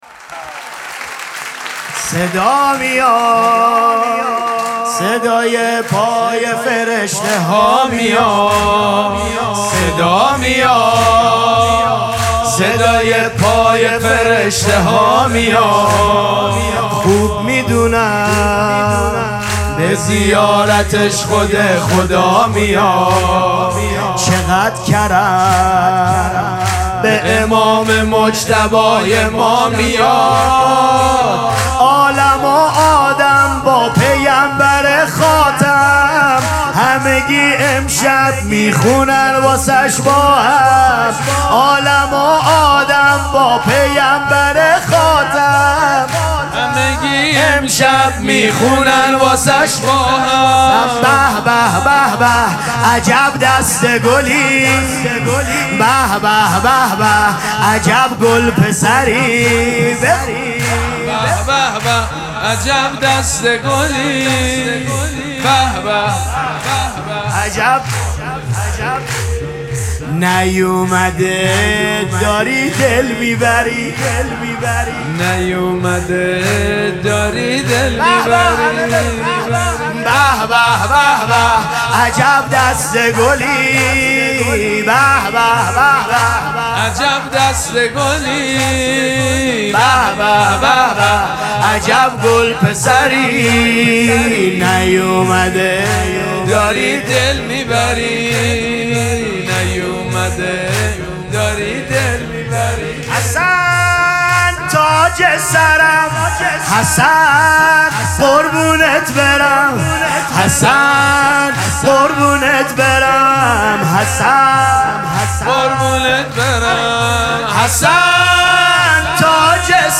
مراسم جشن شام ولادت امام حسن مجتبی(ع)
سرود